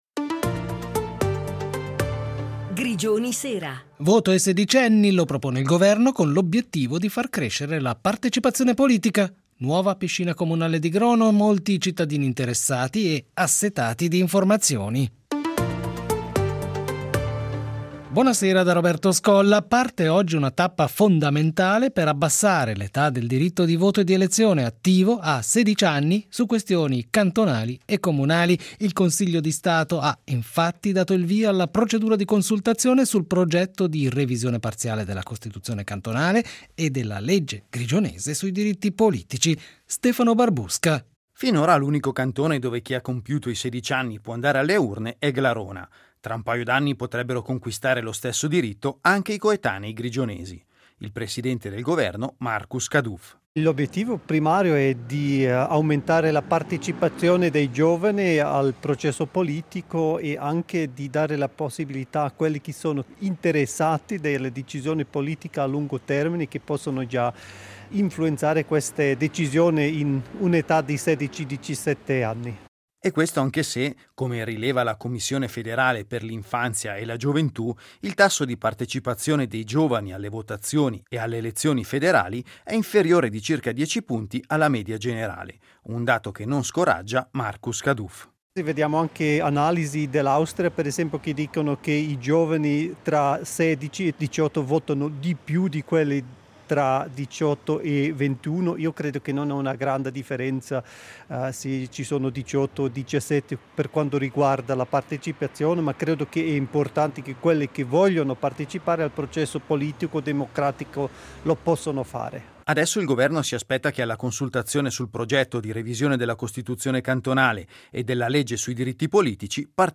Un radiogiornale quotidiano, da lunedì a venerdì, sull'attualità grigionese. Un compendio degli spunti più significativi dell’attualità retica destinato agli italofoni residenti in tutto il paese.